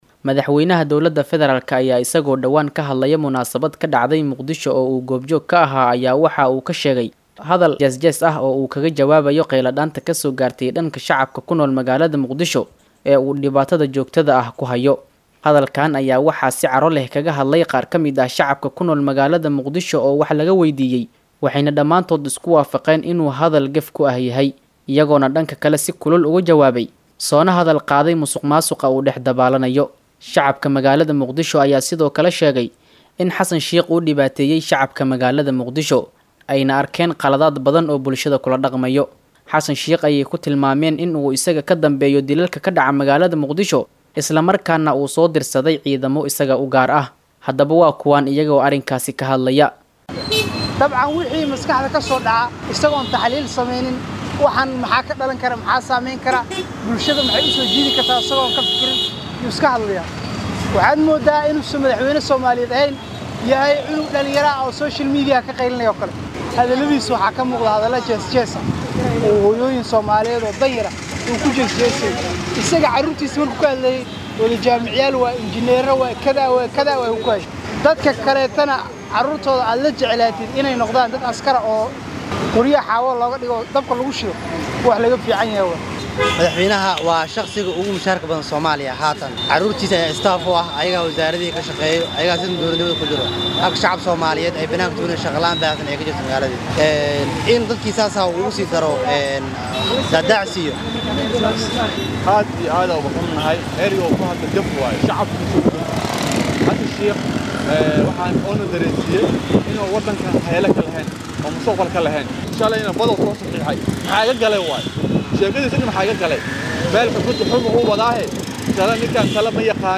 Qaar kamid ah shacabka ku nool Muqdisho oo warbaahinta la hadlay ayaa si kulul ugu jawaabay Xasan Sheekh oo dhawaan hadal jees jees ku ah fagaare lasoo istaagay.
Hadalkan ayaa waxaa si caro leh kaga hadlay qaar kamid ah shacabka ku nool magaalada Muqdisho oo wax laga weydiiyay, waxayna dhamaantood isku waafaqeen inuu hadal gef ku ah yahay, iyagoo dhanka kale si kulul ugu jawaabay, soona hadal qaaday musuq maasuqa uu dhex dabaalanayo.